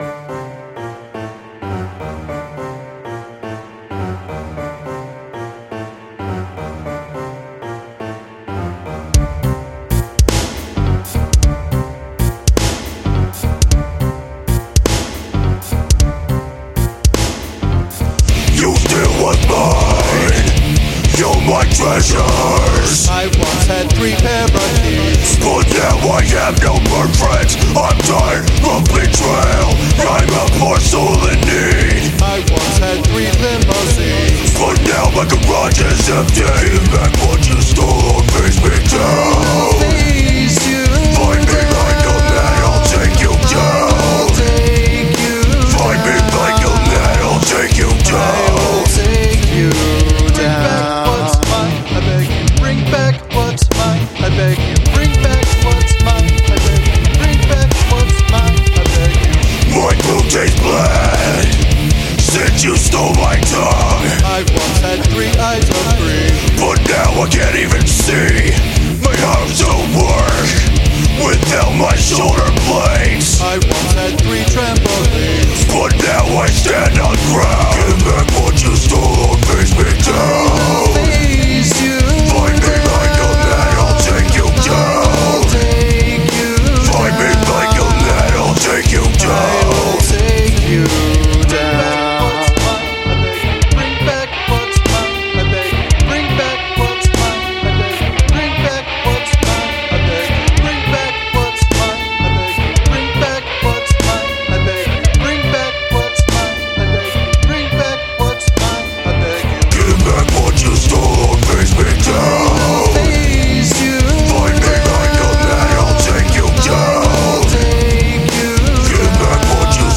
live guitar?!!?!?!?
heavy metal with the lyrics works into the theme.